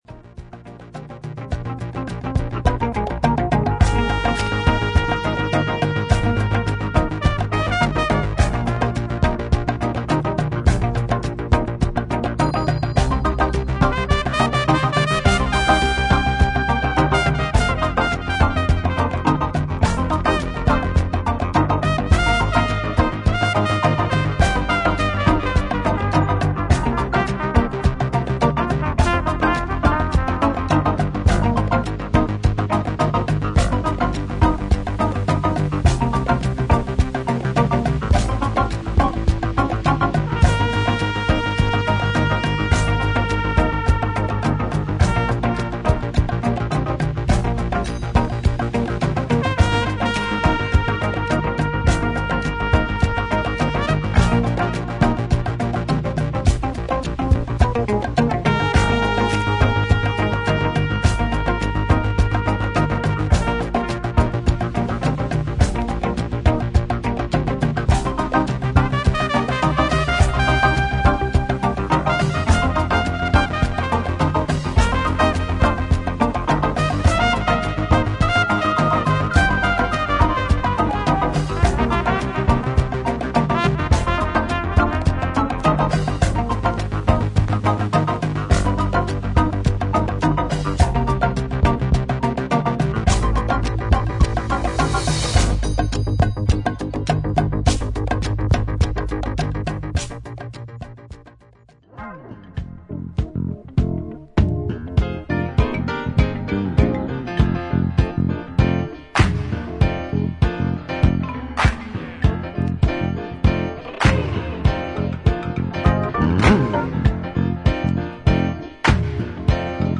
パーカッシブなリズムの上を、ミニマルなシンセ・リフやシンセ・ベースが乗り